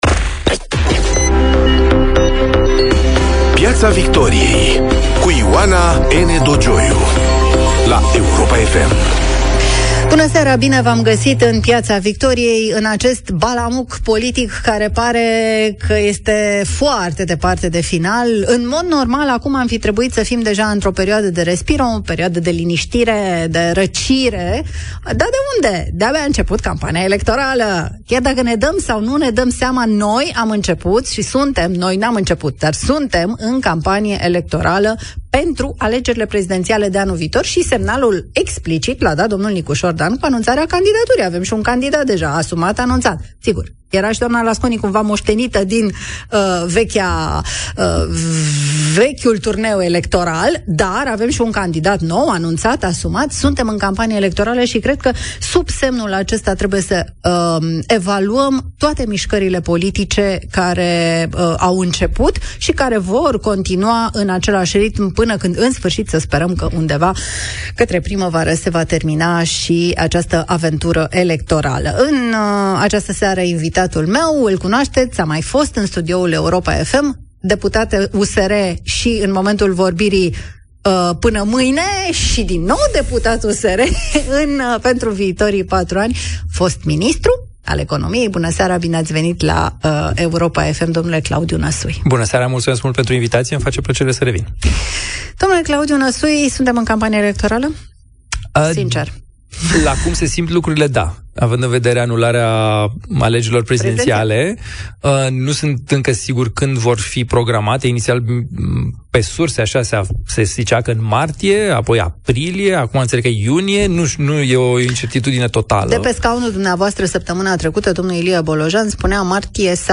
Invitat este Dragoș Tudorache, europalamentar din grupul Renew Europe